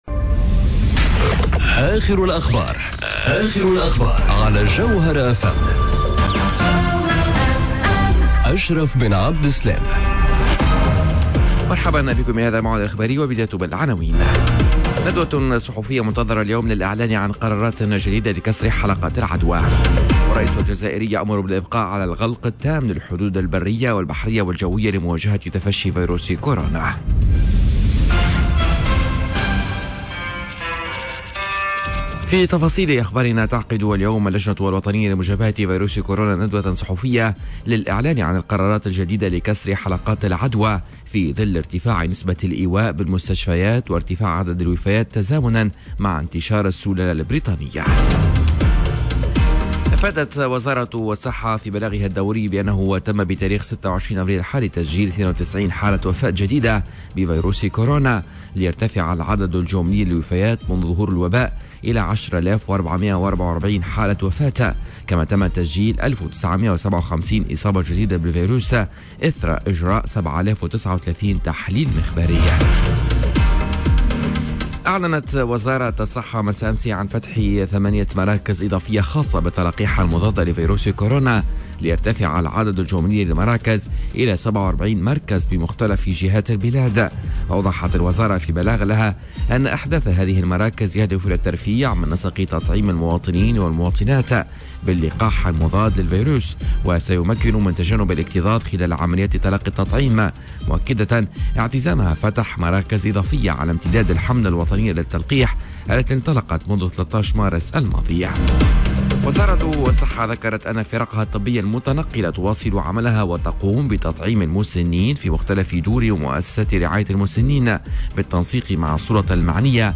نشرة أخبار السابعة صباحا ليوم الإربعاء 28 أفريل 2021